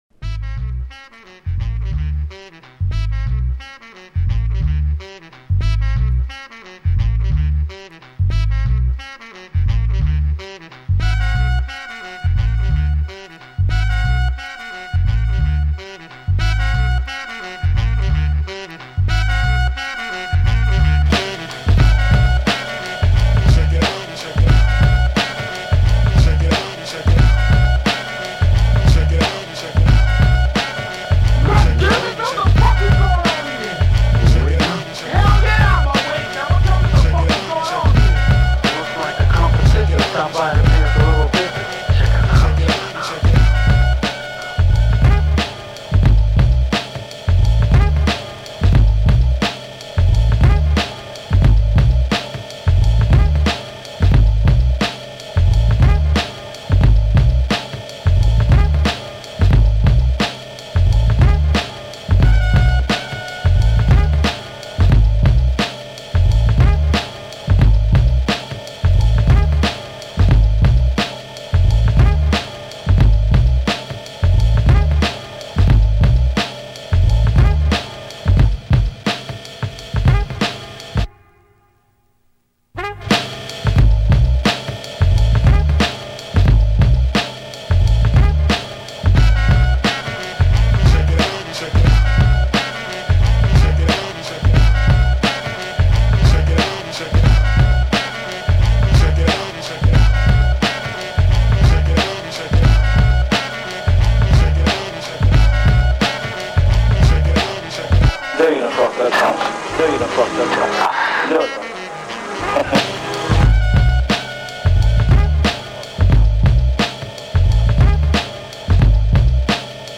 This is the official instrumental